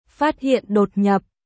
1. Âm thanh lời chào tiếng việt